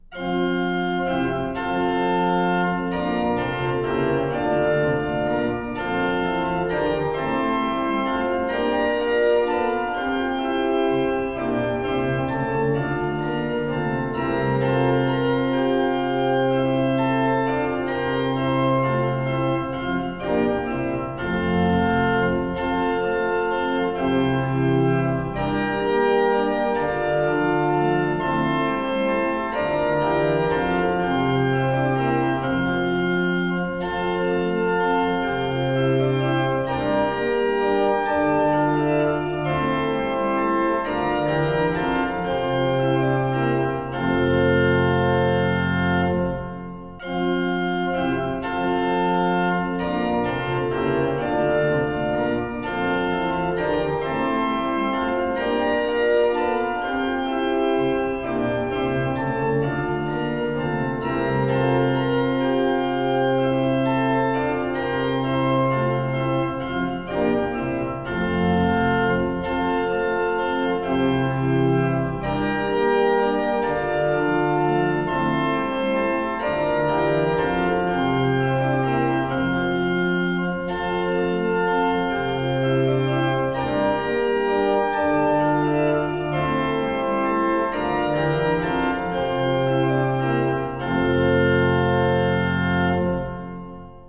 accompagnement pour le respect du tempo et de la rythmique à trois temps